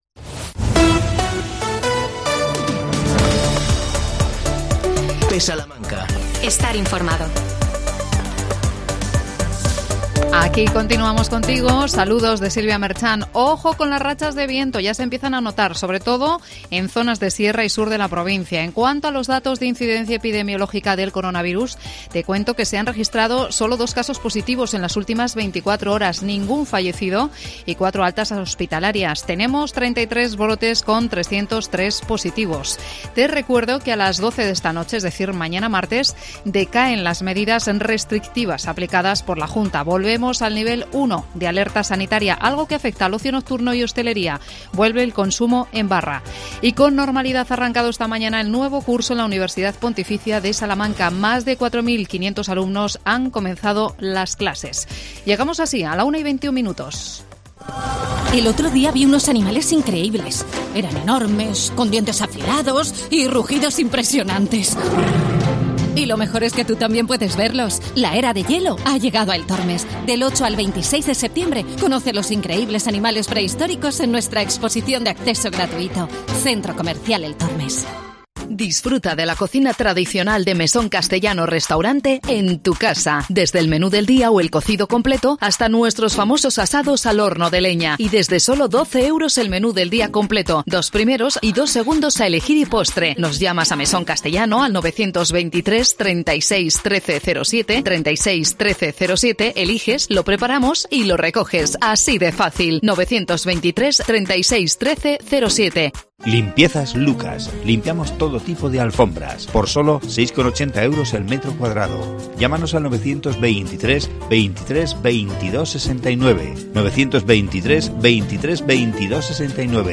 AUDIO: 21 de Septiembre: Día Mundial del Alzheimer. Entrevistamos